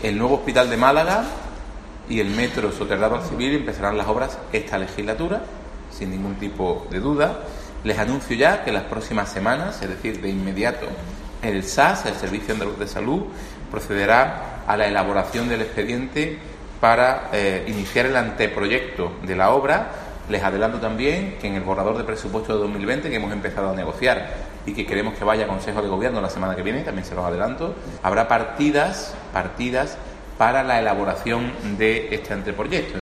El consejero de Presidencia, Elías Bendodo, sobre el tercer hospital